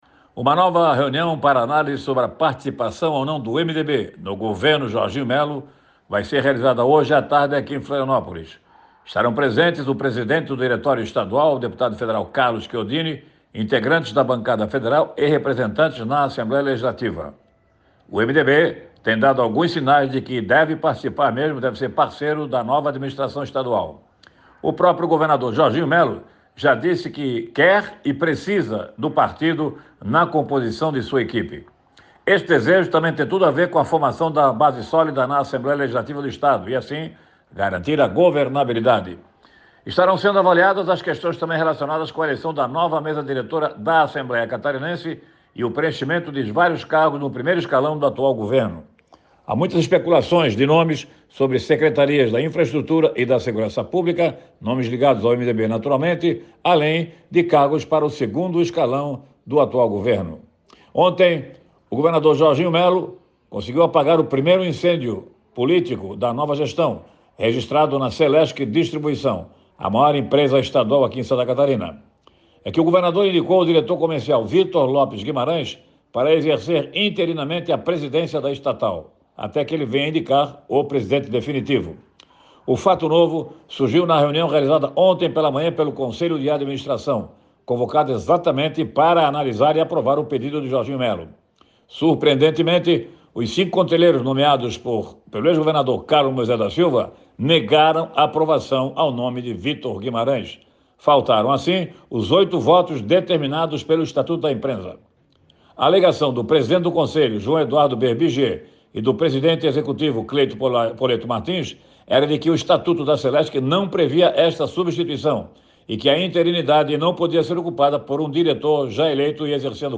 Confira na íntegra o comentário